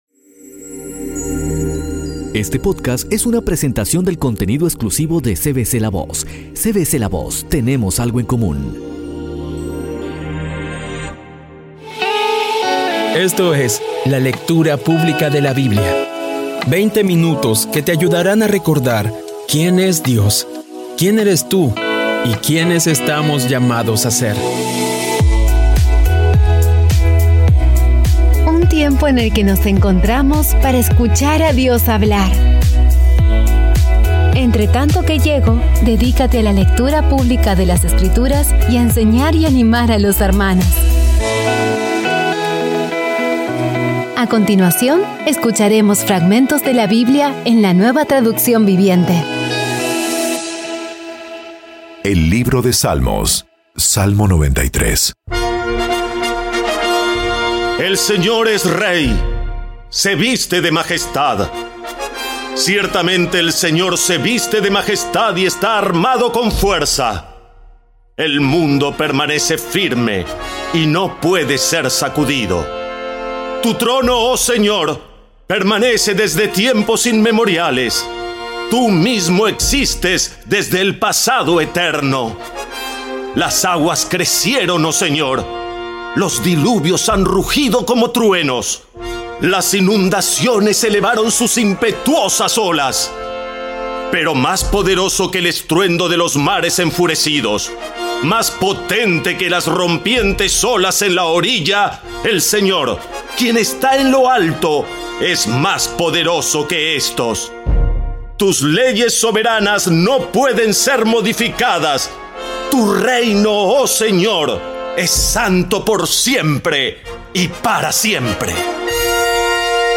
Audio Biblia Dramatizada por CVCLAVOZ / Audio Biblia Dramatizada Episodio 227